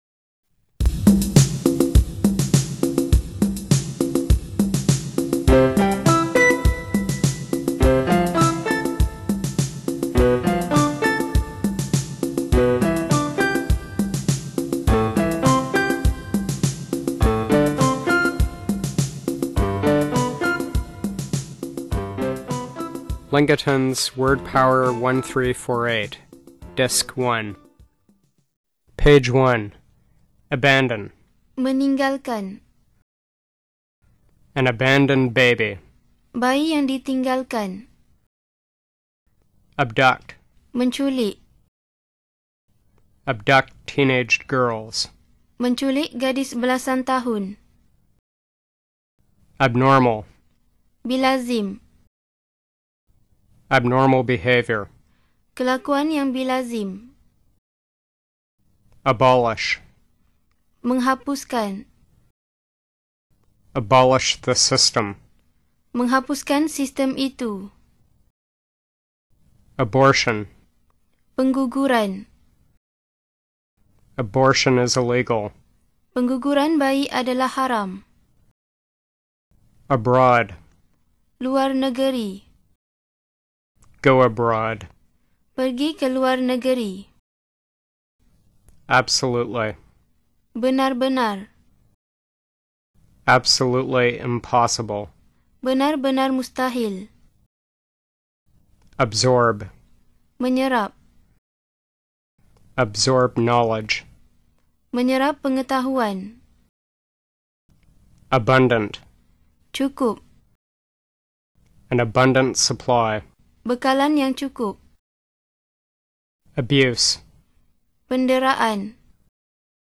3. Buku ini desertakan dengan CD dibaca dalam Bahasa Inggeris dan Bahasa Malaysia.